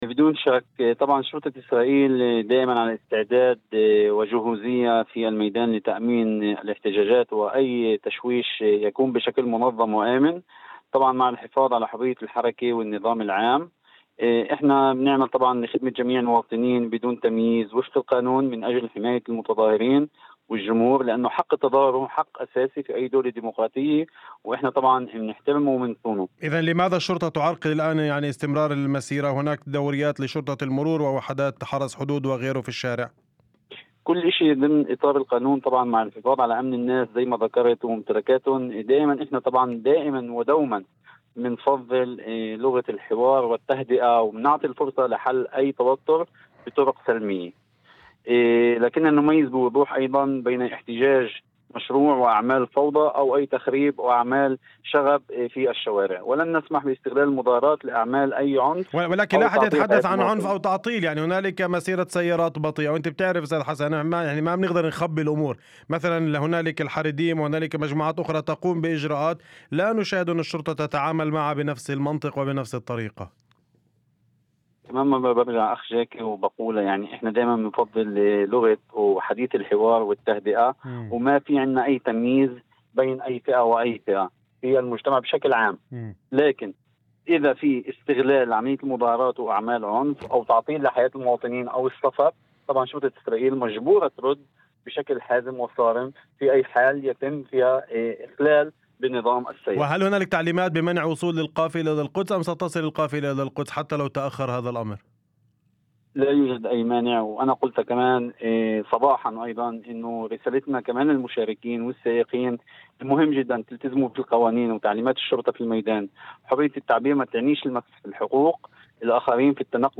وأضاف في مداخلة هاتفية لبرنامج "الظهيرة"، على إذاعة الشمس، أن انتشار دوريات الشرطة ووحدات شرطة المرور وحرس الحدود في عدة نقاط على طول مسار المسيرة جاء في إطار تنظيم الحركة ومنع أي مخاطر على أمن المشاركين أو مستخدمي الطريق.